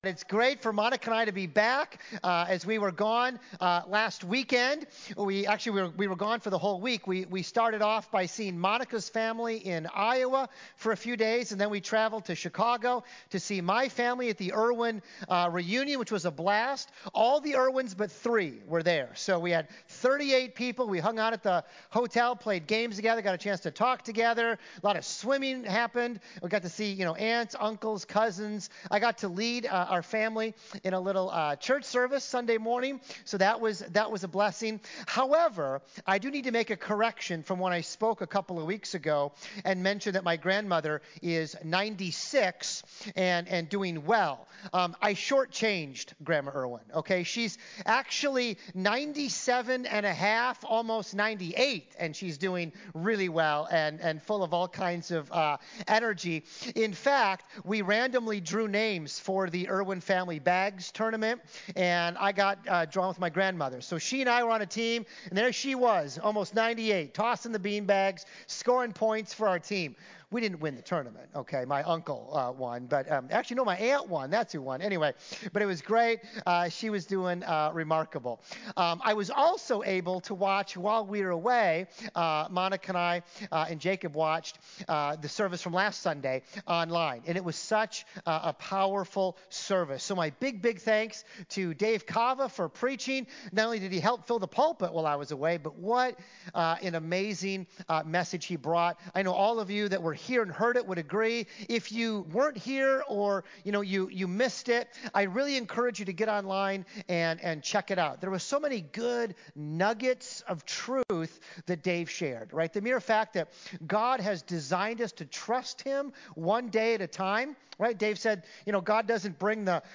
Dix Hills Church